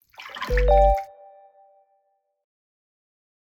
login.wav